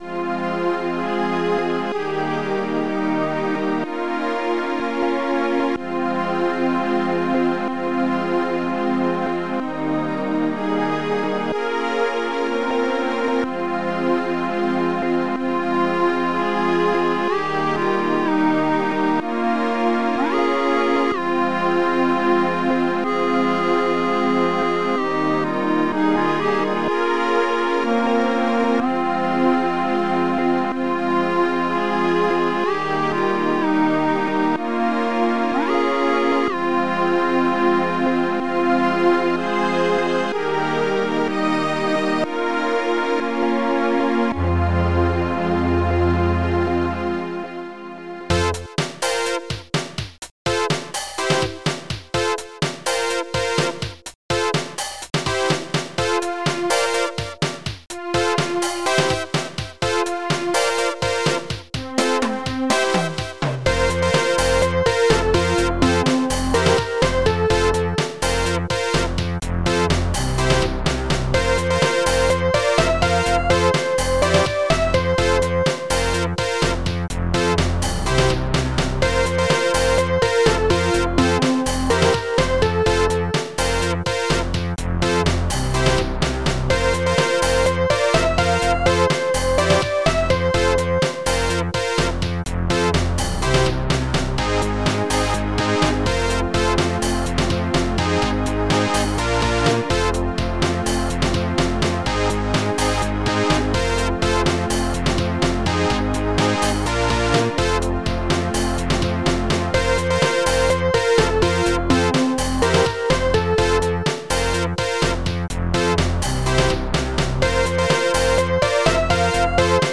Protracker Module  |  1990-11-02  |  194KB  |  2 channels  |  44,100 sample rate  |  8 minutes, 53 seconds
st-10:stringpadmaj
ST-09:DDBass1
st-10:snare1
ST-09:kick1
ST-09:Clarinet